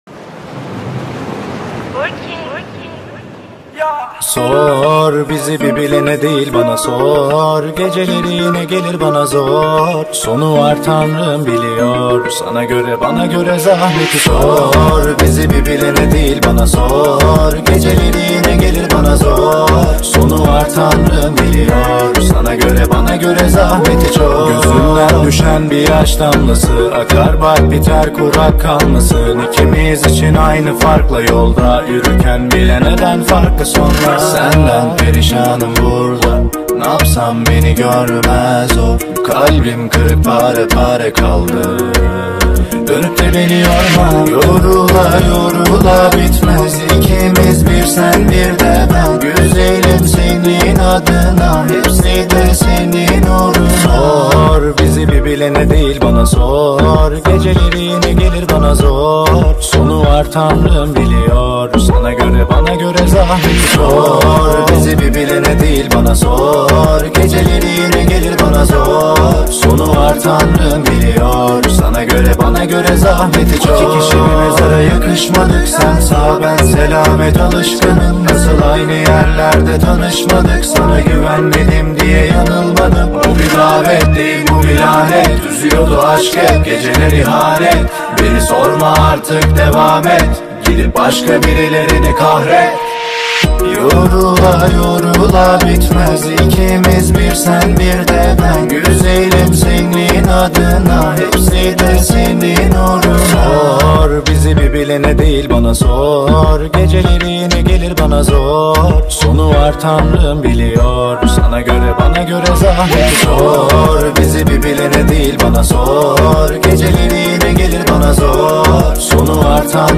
Скачать музыку / Музон / Турецкая Музыка